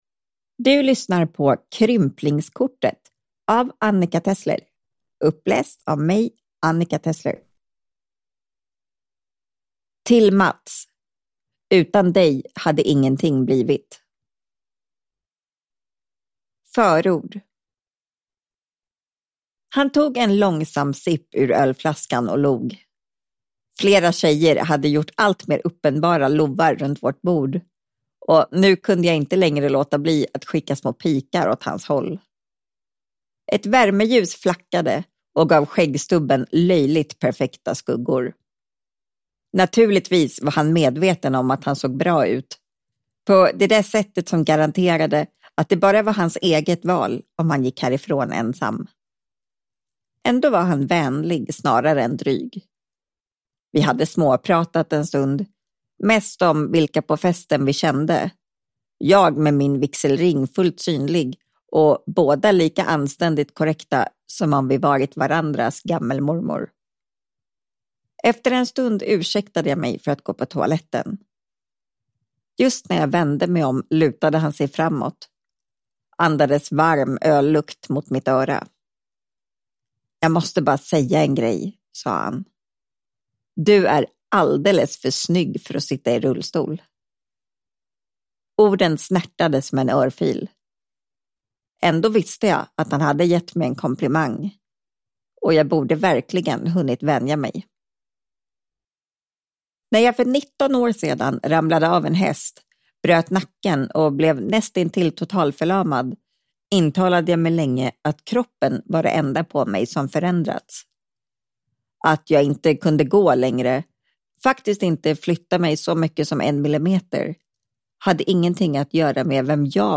Krymplingskortet – Ljudbok – Laddas ner